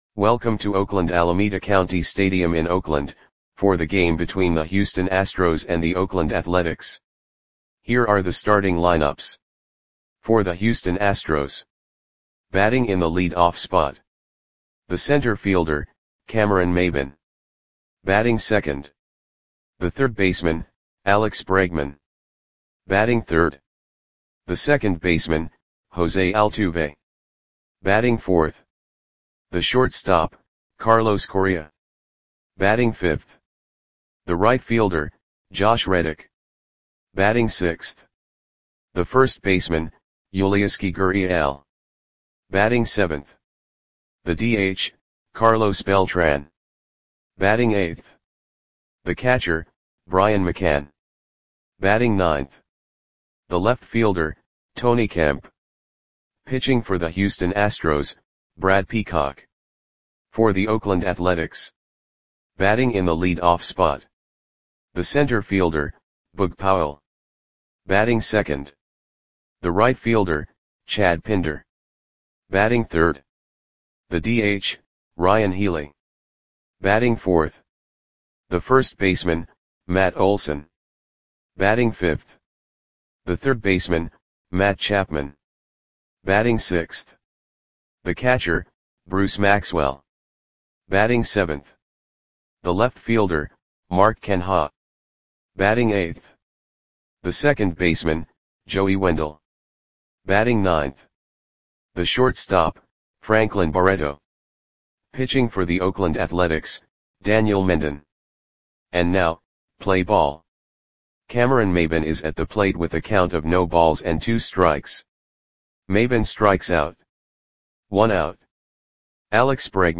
Audio Play-by-Play for Oakland Athletics on September 9, 2017
Click the button below to listen to the audio play-by-play.